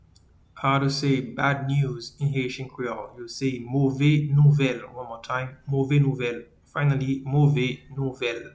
Pronunciation:
Bad-news-in-Haitian-Creole-Move-nouvel-.mp3